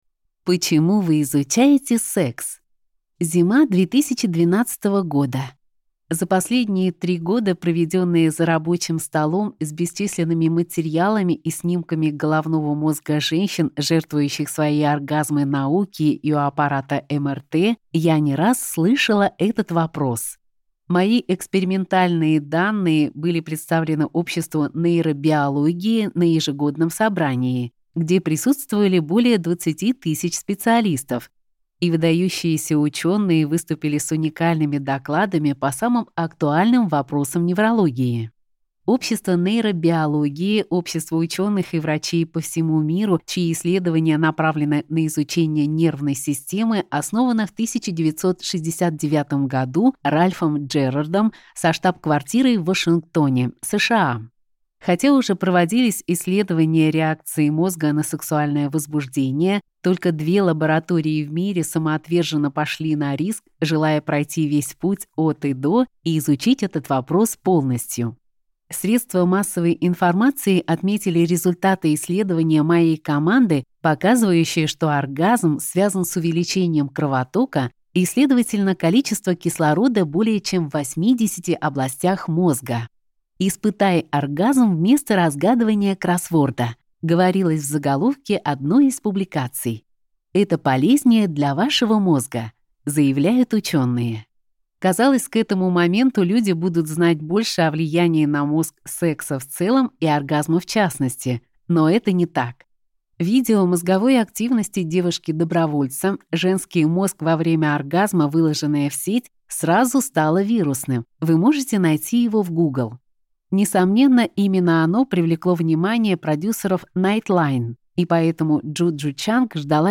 Аудиокнига Больше хорошего секса! Как научить мозг получать удовольствие от секса и не только | Библиотека аудиокниг